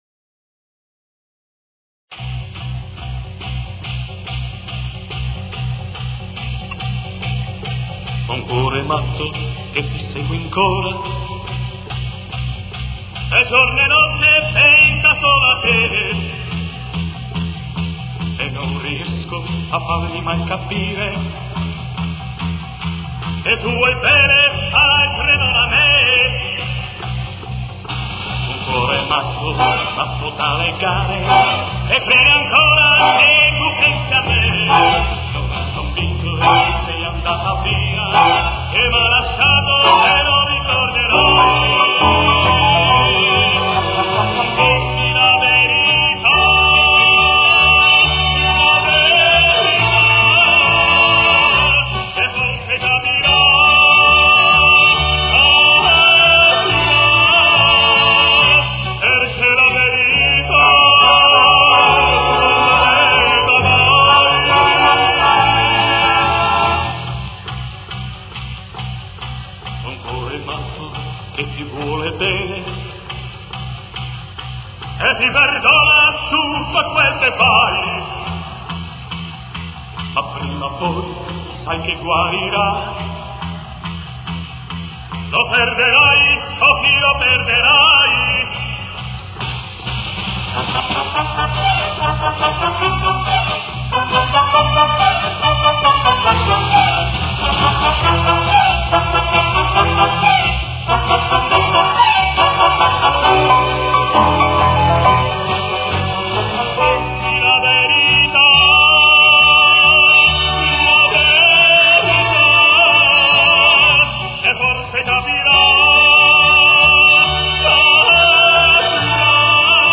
Темп: 142.